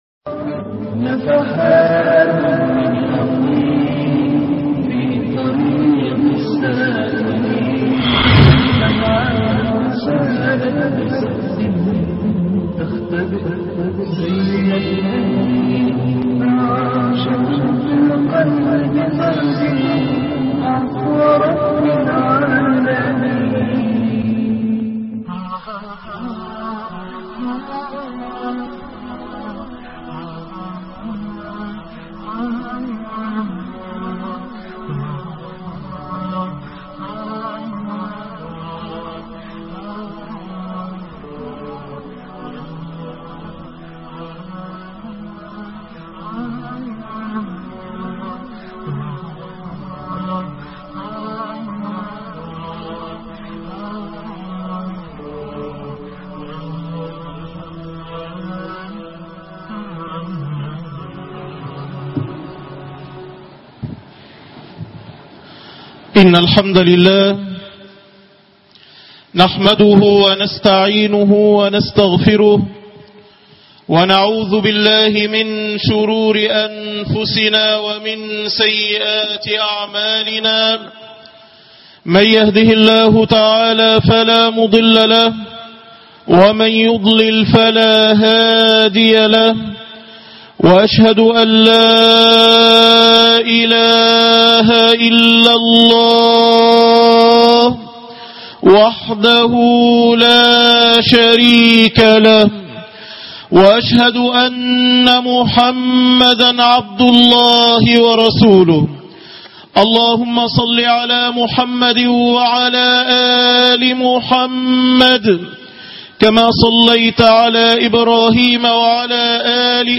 مريم وصاحب السعادة- خطب الجمعة